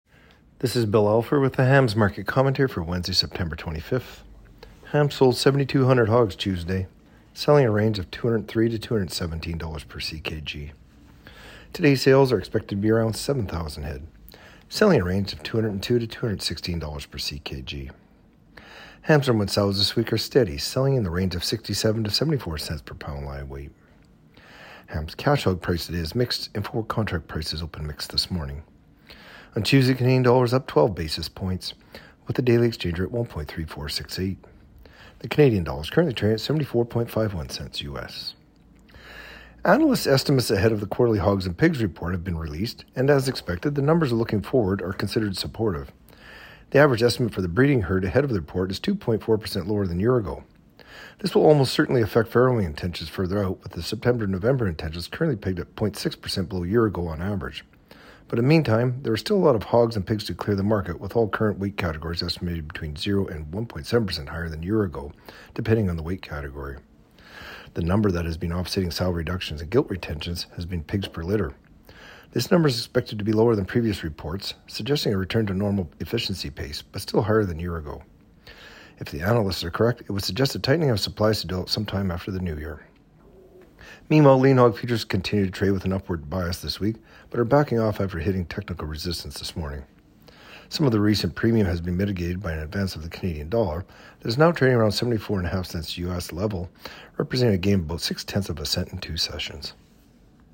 Market-Commentary-Sep.-25-24.mp3